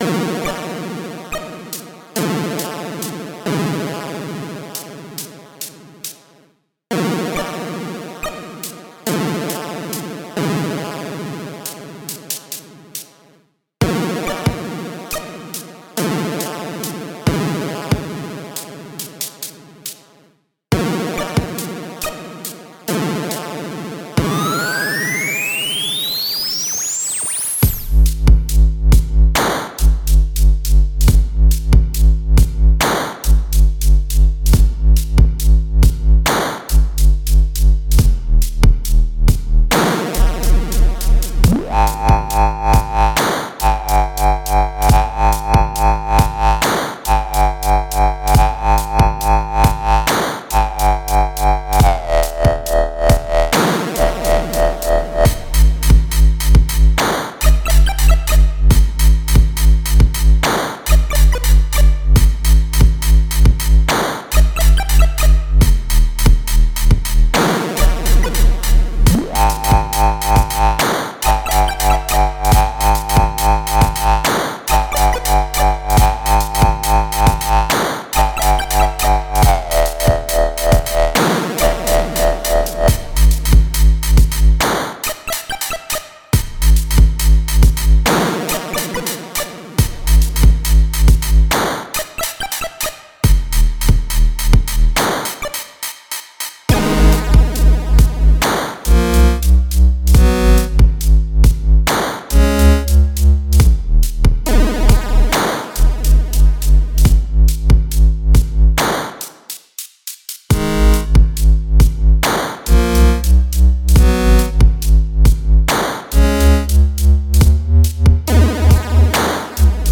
Bass: C64 (SID 6581) + LFO
Lead & Effects: C64, GB & NES
Drums: C64 & Atari 2600
Some of the noises are incredibly weird.
Chipstep